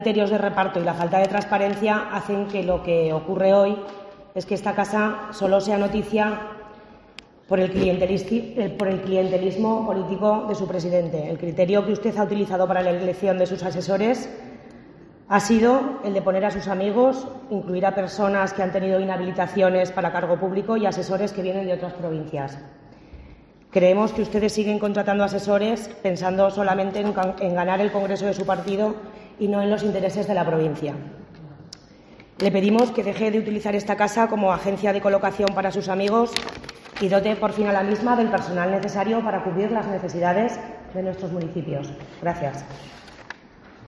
Carmen Lázaro, diputada de la DPZ, denuncia clientelismo en la institución
Declaraciones que ha realizado en el Pleno Extraordinario que ha acogido esta institución durante esta jornada.